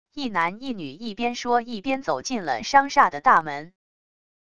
一男一女一边说一边走进了商厦的大门wav音频